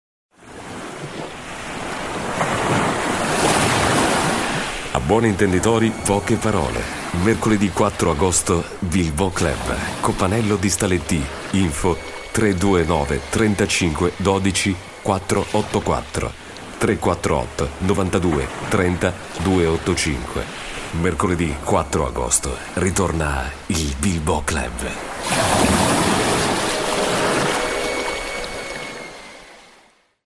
Voce classica radiofonica.
Sprechprobe: Werbung (Muttersprache):